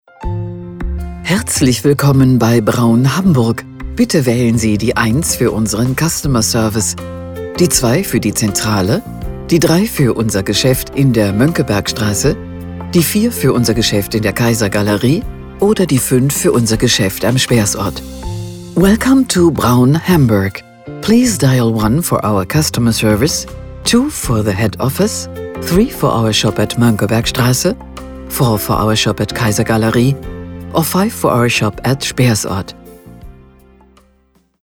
Telefonansagen mit echten Stimmen – keine KI !!!
IVR Ansage – Braum Hamburg – exklusive Herrenmode